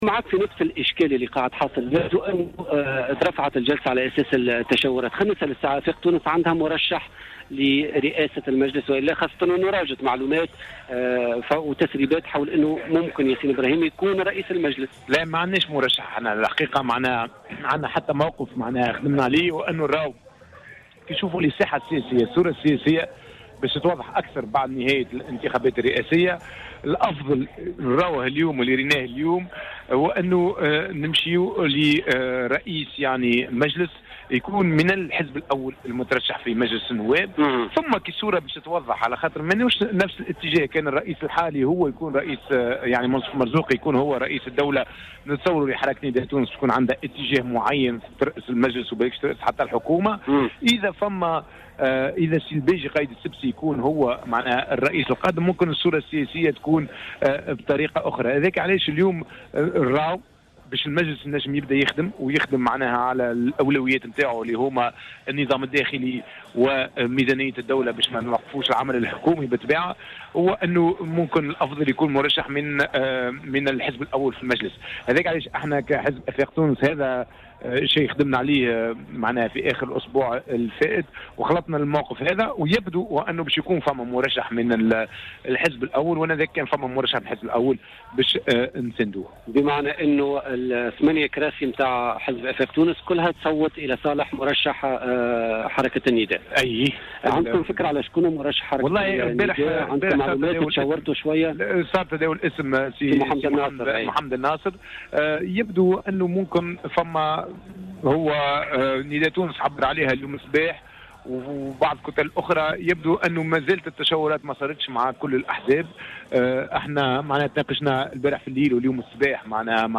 أكد أمين عام حزب آفاق تونس ياسين ابراهيم اليوم في مداخلة له في برنامج "بوليتيكا" أن حزبه لن يقدّم مرشّحا لرئاسة مجلس الشعب الجديد.